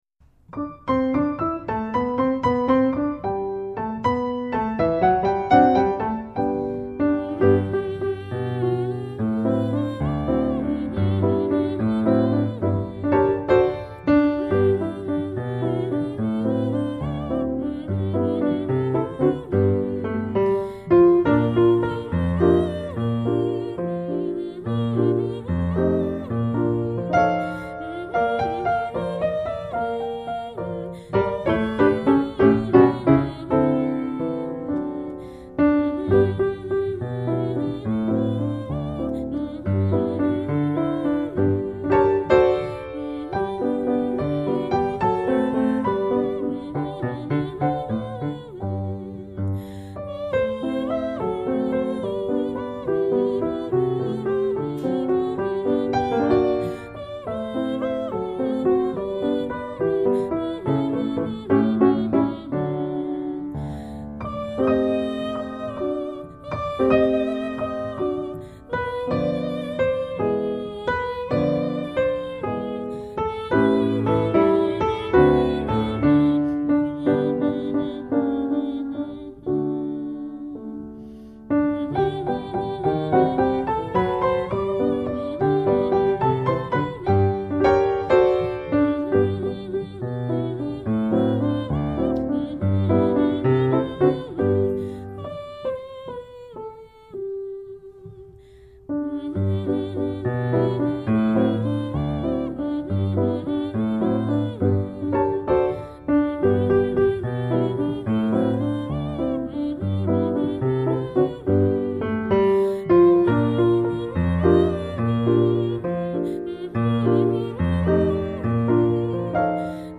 Audio voix et piano